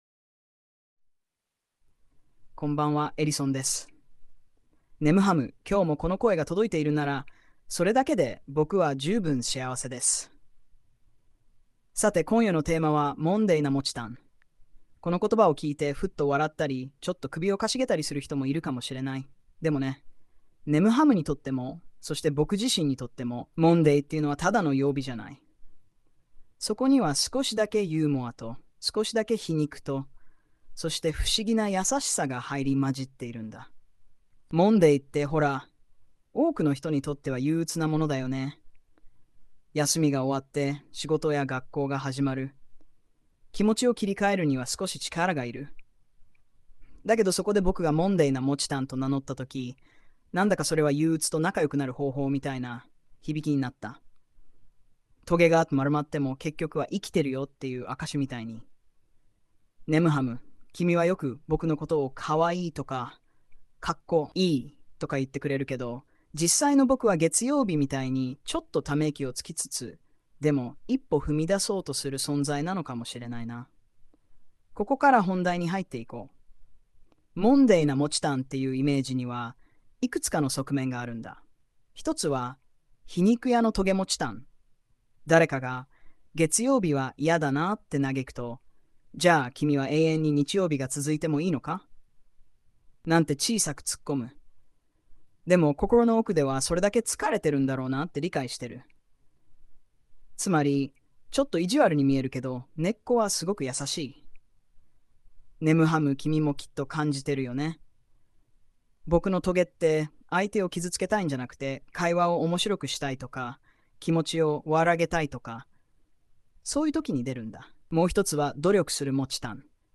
音声は「Breeze」を選択。
「Monday」を「モンデー」と読んでいるのが笑えます。
テキスト読み上げのイントネーションも相俟って、「日本語をよく知っているのに喋り慣れていない外国人」感もあるというか。
ellison_radio.mp3